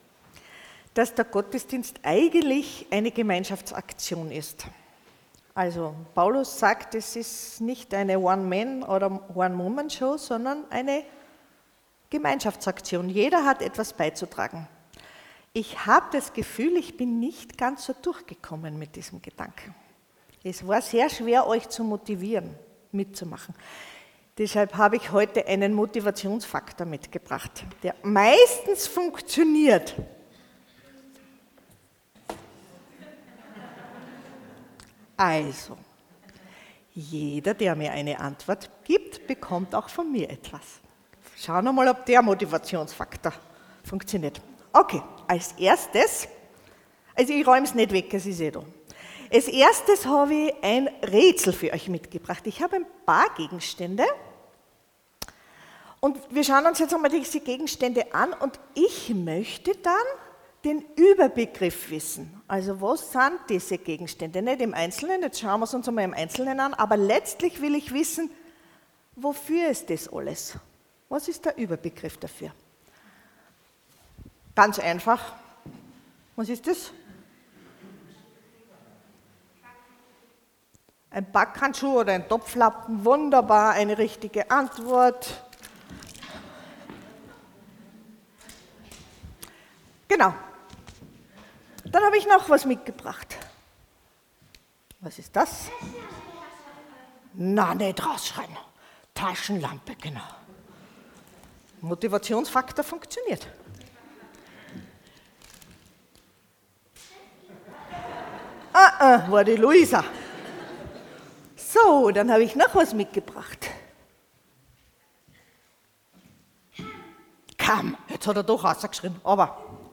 Gottes allumfassende Liebe – Familiengottesdienst